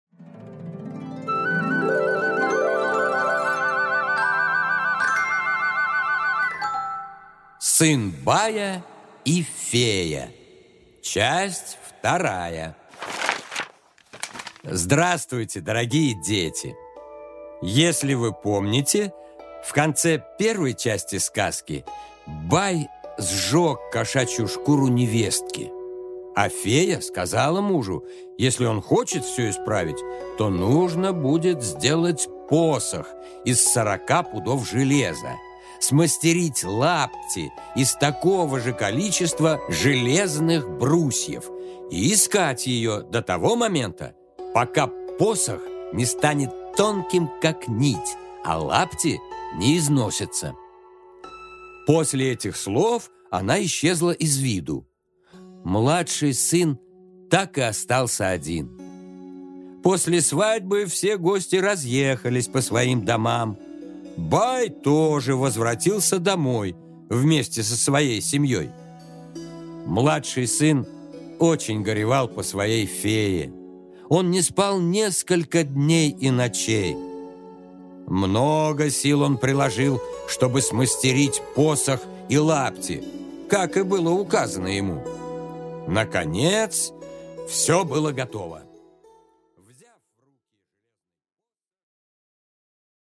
Аудиокнига Сын бая и фея 2-часть | Библиотека аудиокниг